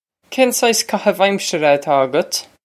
Pronunciation for how to say
Kane sice kah-hiv eyeim-sher-a a-taw a-gut?
This is an approximate phonetic pronunciation of the phrase.